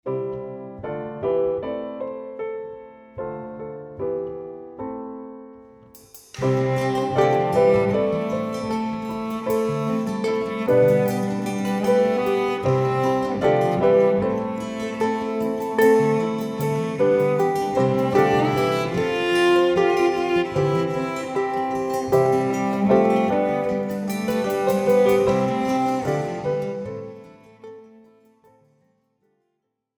Besetzung: 1-2 Altblockflöten